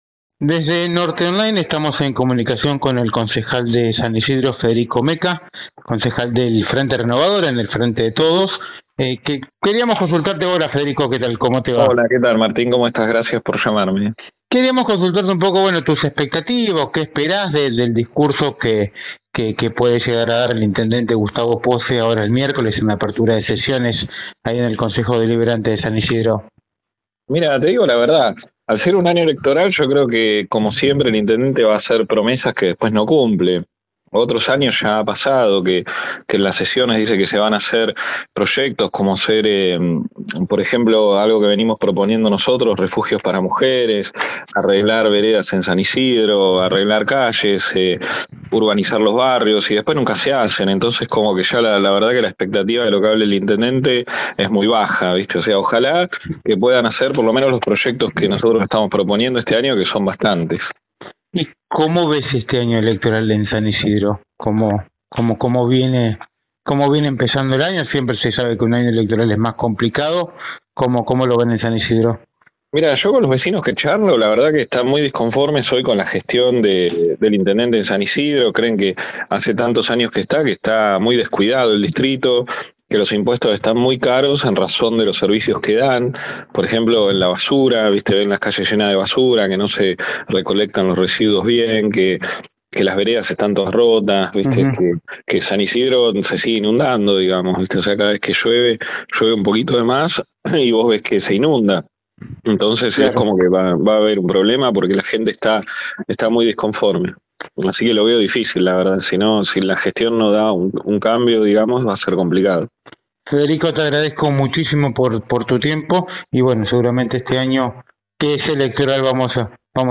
El concejal del Frente Renovador en San Isidro dialogó en exclusiva con NorteOnline, en la antesala de la apertura de sesiones ordinarias a cargo del jefe comunal.